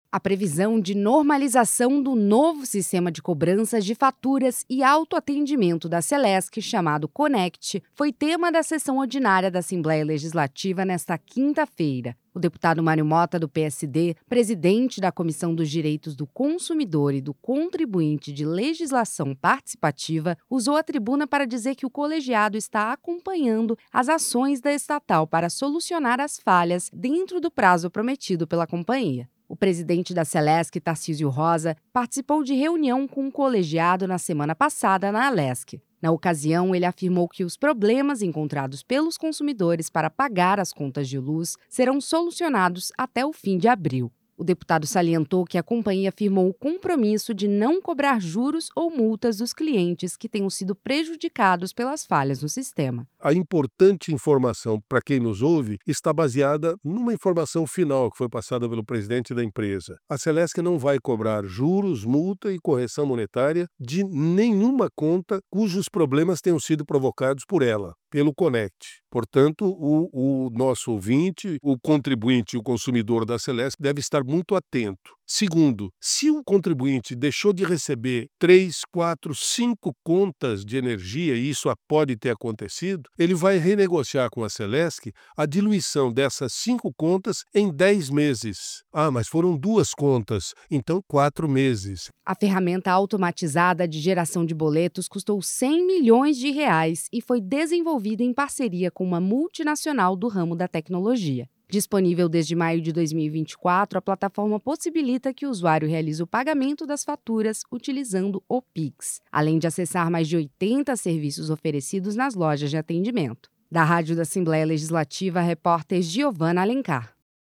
Entrevista com:
- deputado Mário Motta (PSD).